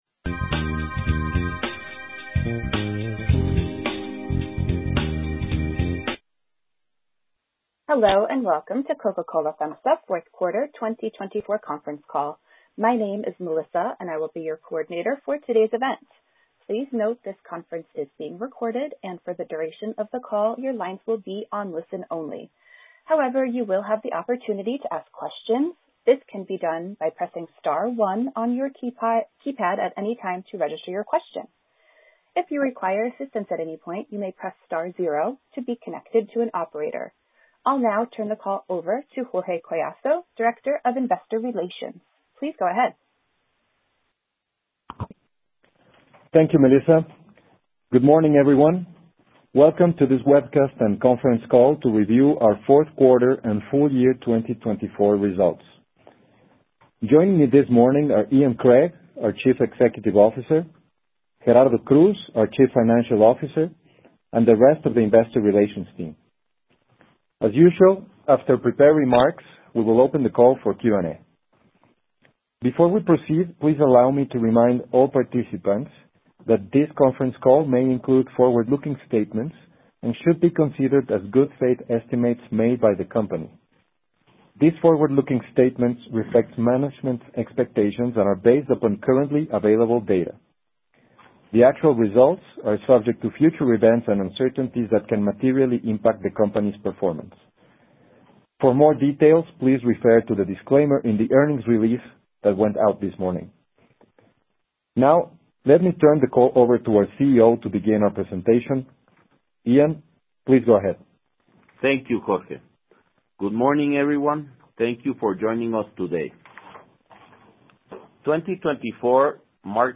Audio de la conferencia telefónica